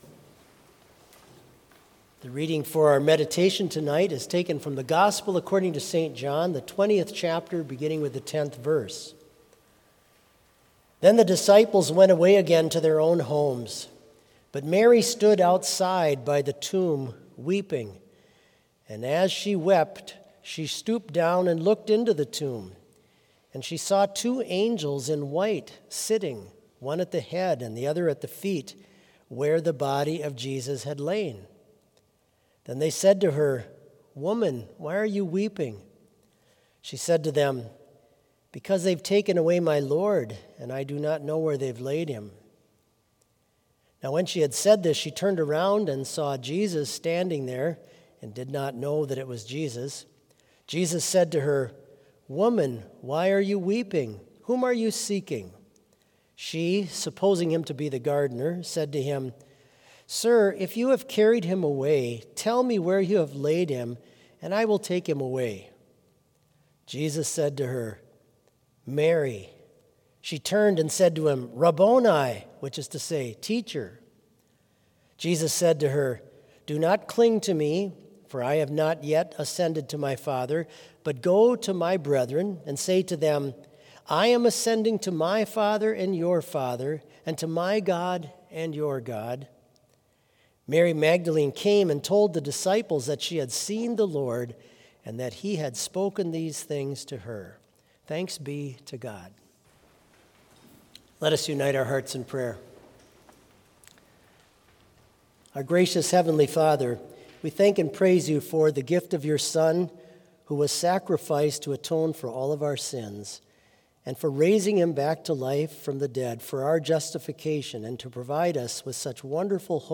Complete service audio for Vespers - April 12, 2023